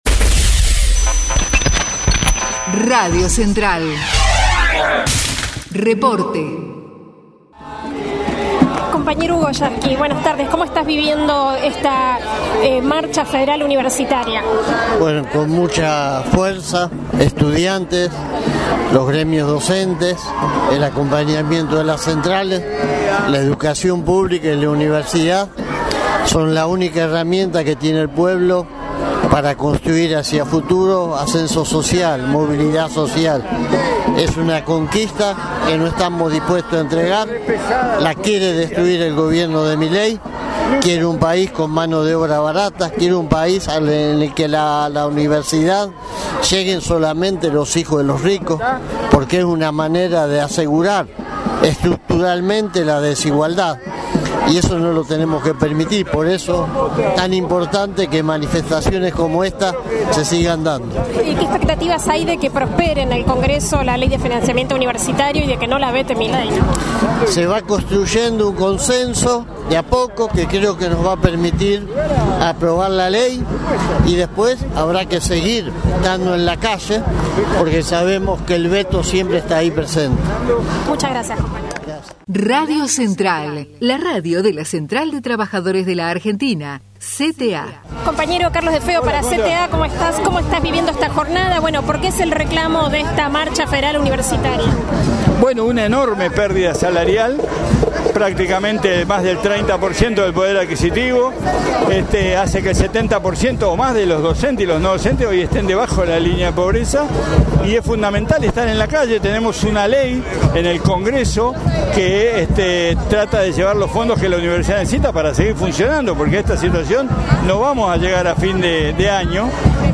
2025_marcha_federal_universitaria.mp3